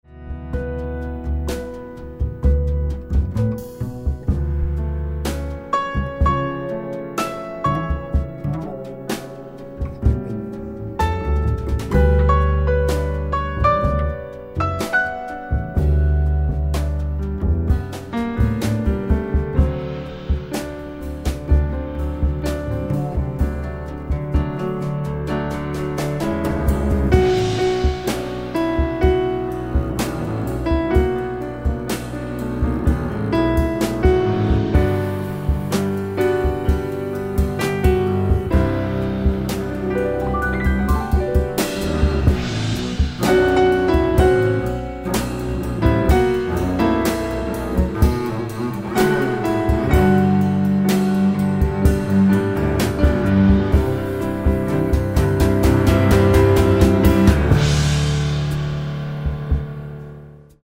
drums
acoustic bass